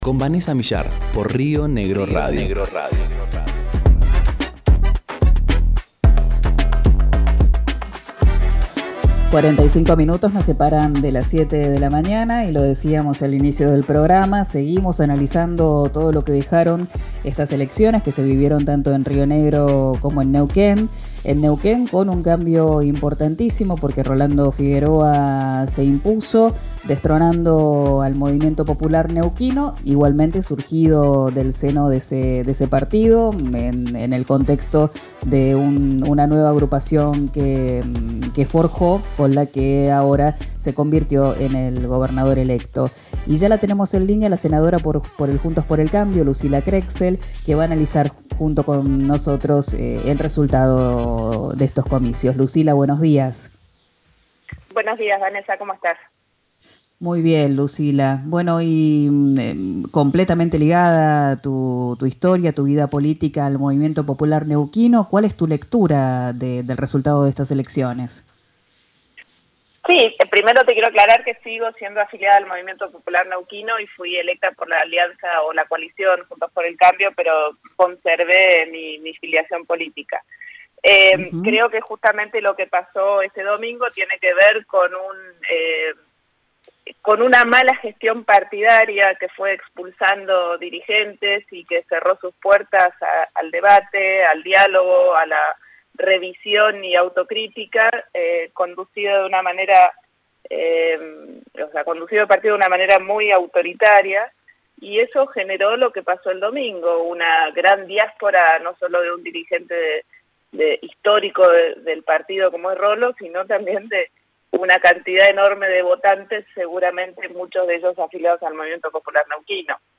Para la senadora, el 'autoritarismo' del gobernador de Neuquén y presidente del partido 'expulsó' a dirigentes y votantes. Escuchá la entrevista en RÍO NEGRO RADIO.